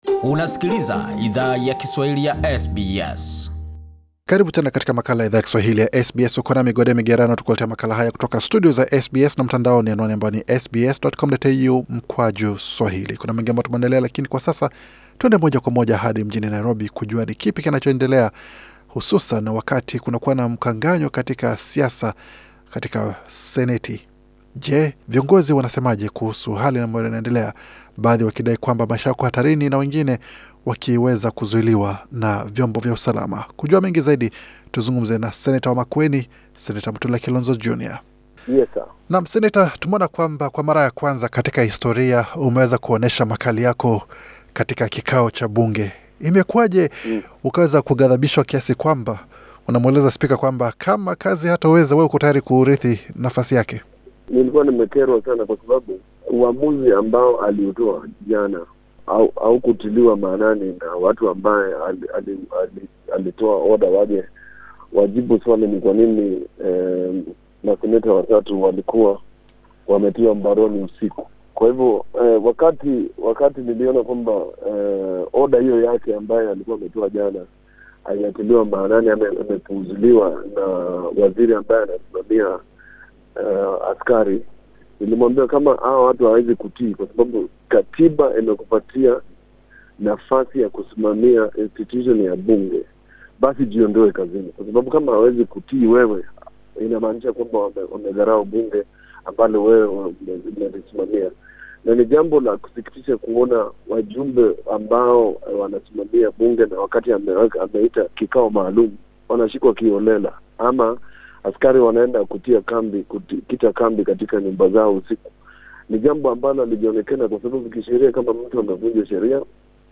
Katika mazungumzo maalum na Idhaa ya Kiswahili ya SBS, Sen Kilonzo Jr alifunguka kuhusu kilicho mkera, hadi akamweleza spika kuwa yuko tayari kufanya kazi hiyo, iwapo spika hayuko tayari kutekeleza wajibu wake.